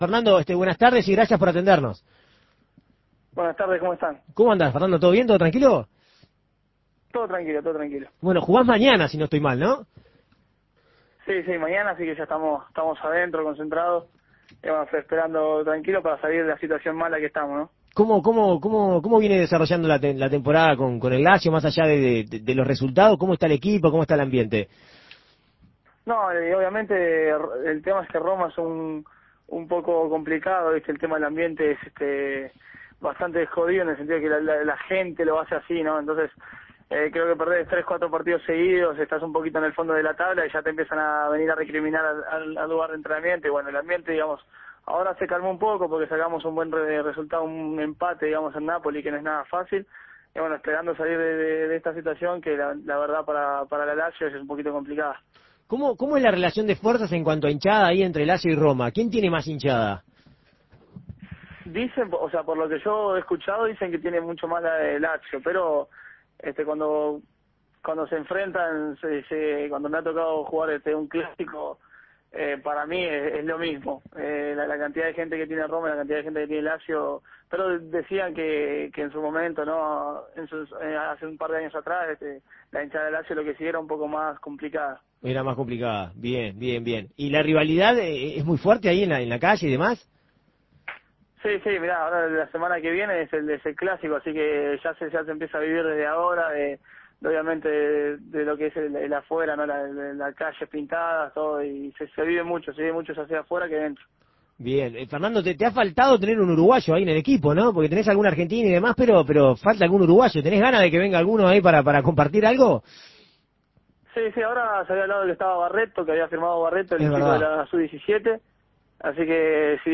El arquero de la selección uruguaya, Fernando Muslera, afirmó en entrevista con El Espectador que todavía no se hace la idea de que clasificó a un mundial con Uruguay.